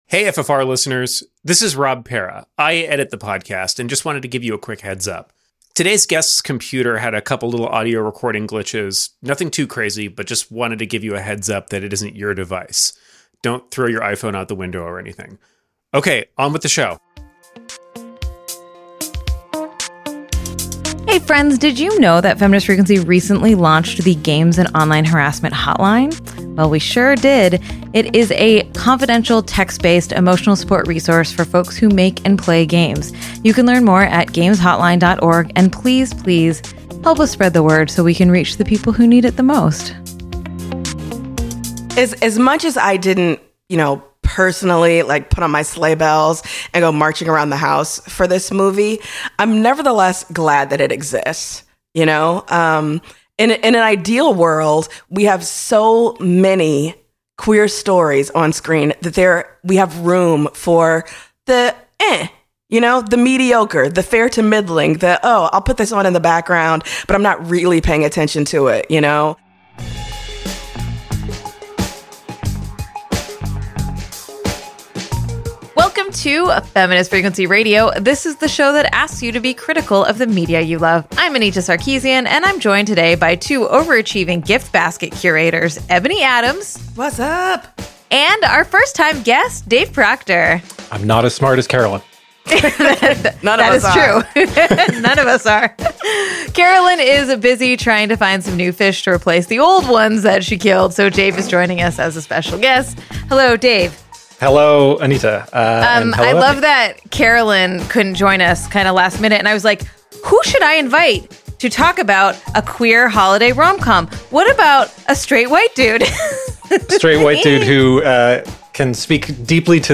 Join us for a lively discourse on this classic political thriller.